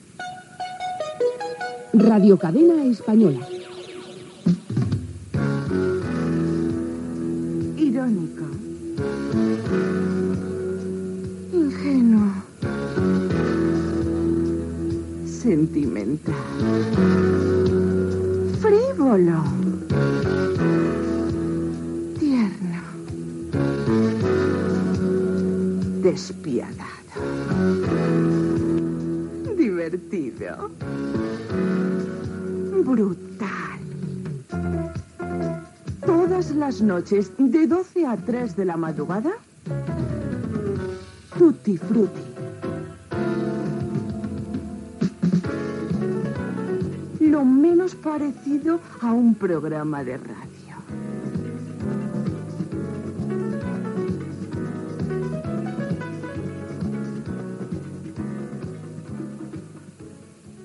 Indicatiu de la ràdio, careta del programa
Entreteniment